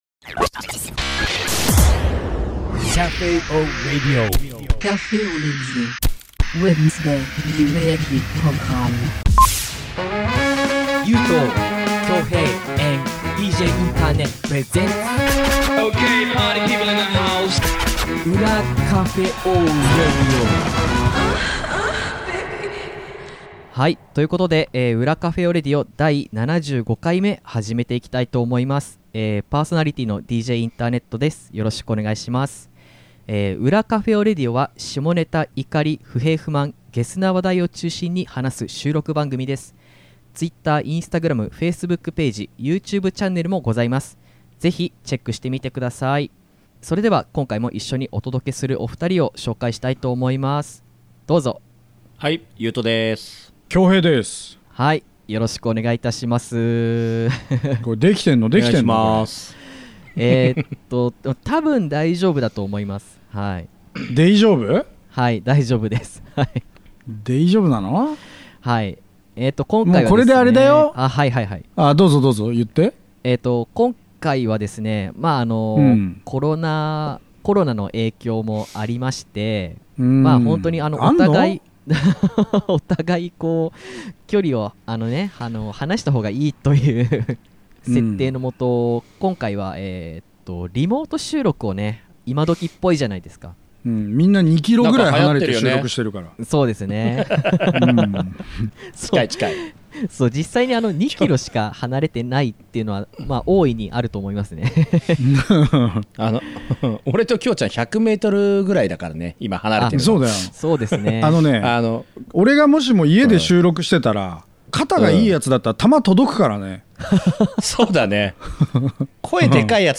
「リモート収録 初夜」 新型コロナウイルス感染拡大の影響を踏まえ 今回の裏Cafe au Radioから、しばらくの間は リモート収録を行うこととなりました！